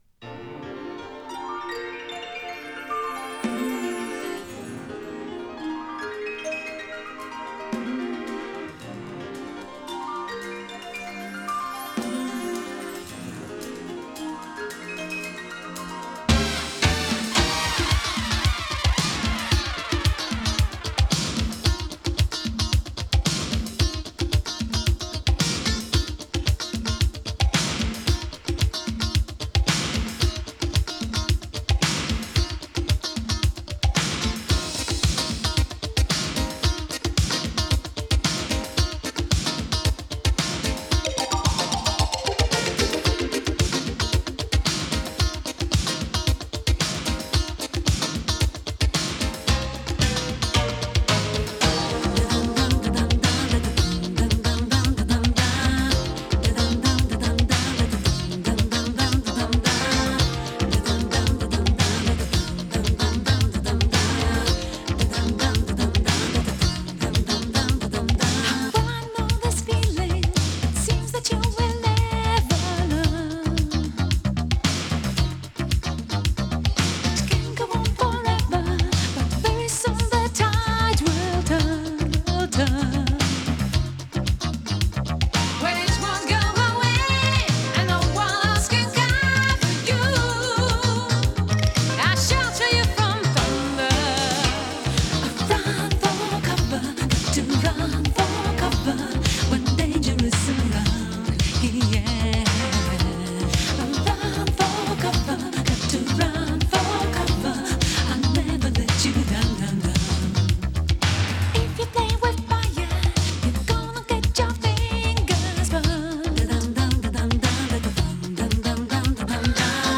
Instrumental 3.38